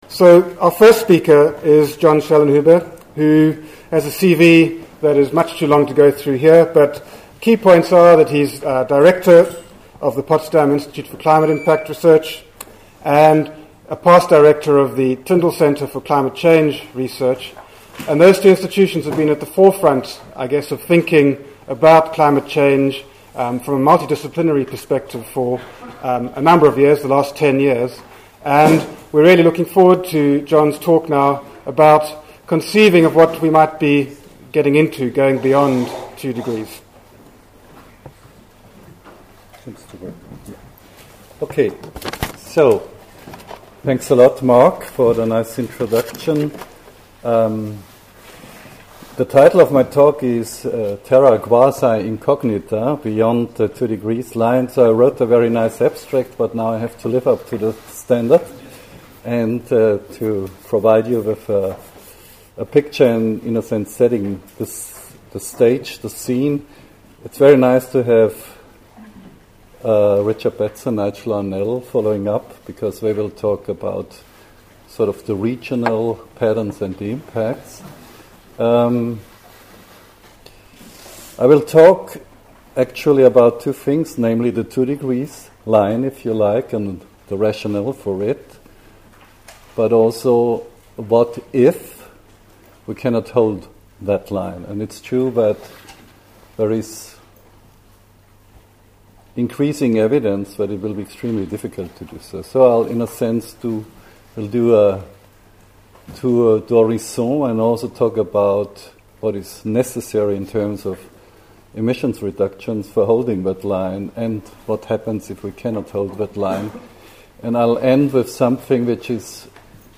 John Schellnhuber, in this keynote speech delivered at the 4 degrees conference held in the UK, discussed risk of a magnitude the anti nuclear types refuse to face.